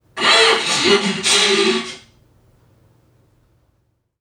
NPC_Creatures_Vocalisations_Robothead [85].wav